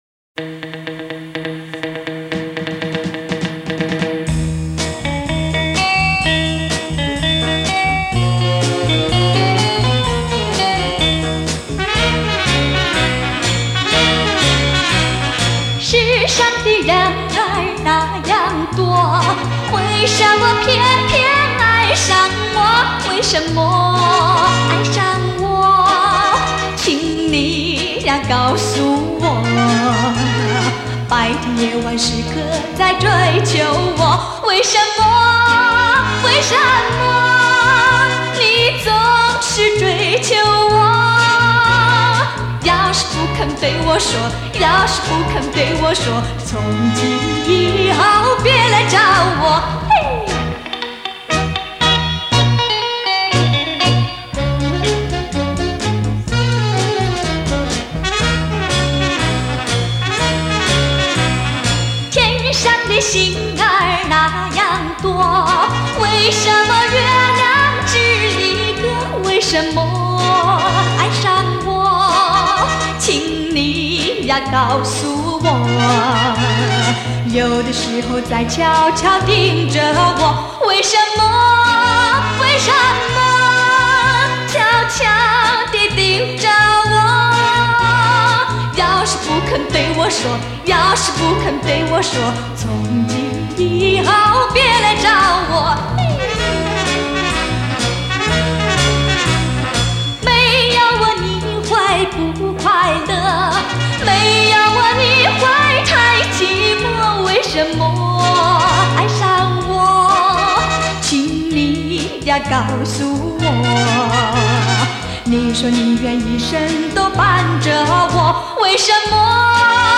本系列采用近三十年时间，最值得珍藏之原唱者母带所录制，弥足珍贵！